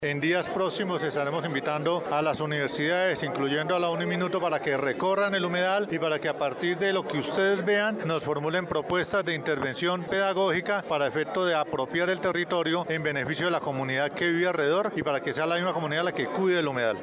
En medio de una ceremonia de premiación por el “I Concurso de Fotografía Ambiental”, el municipio de Soacha conmemoró el Día del Agua.
Una vez concluida la ceremonia de premiación, el director de la CAR, Néstor Franco, habló sobre el trabajo ambiental que se está realizando en alianza con la administración municipal, en esta ocasión, la labor en la recuperación de los humedales de Soacha que ha iniciado por el del Neuta.